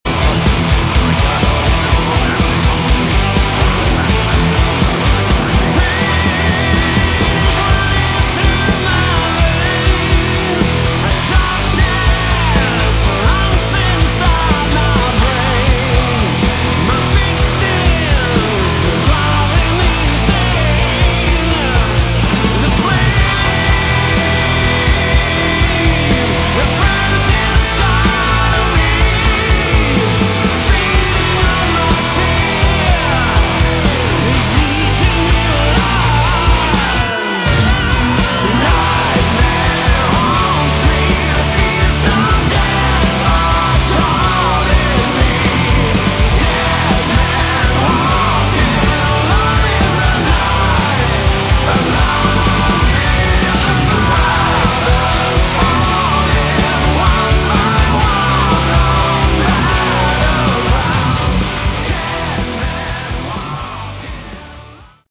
Category: Melodic Metal
vocals
guitars
bass
drums
keyboards